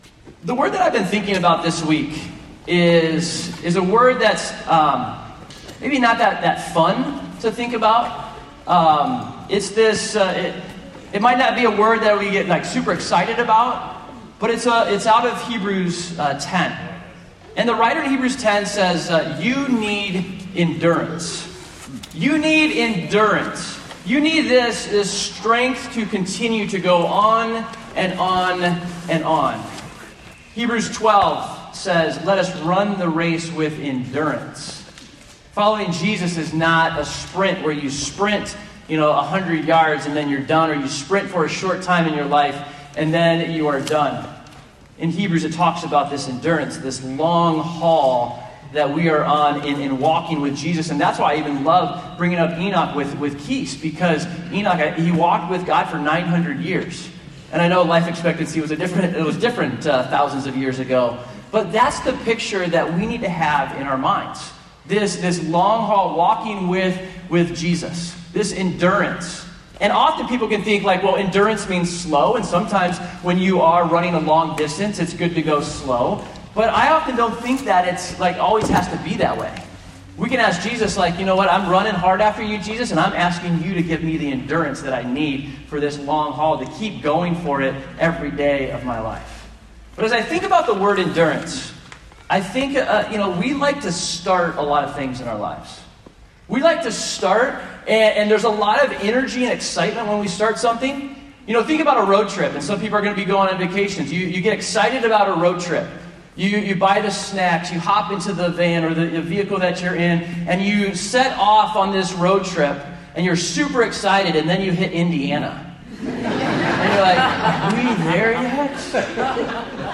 So, towards the end of the message there are two powerful testimonies.